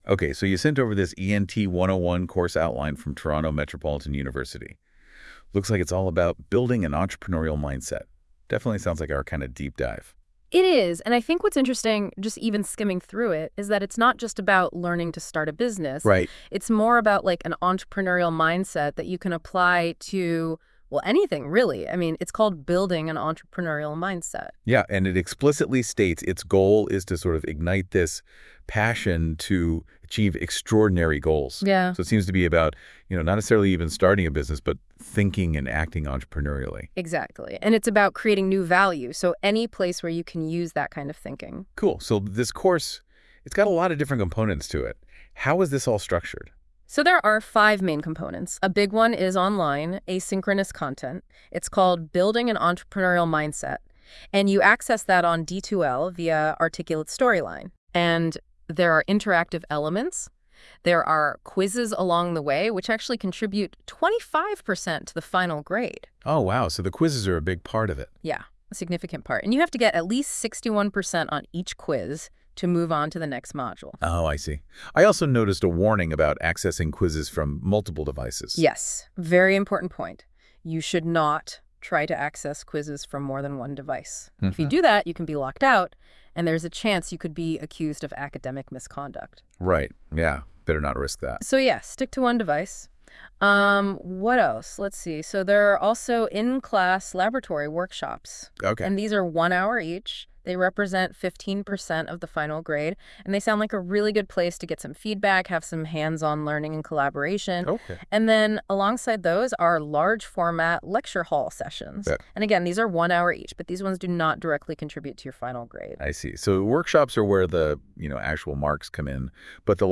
Ep-4-AI-Talk-Show-Week-2.wav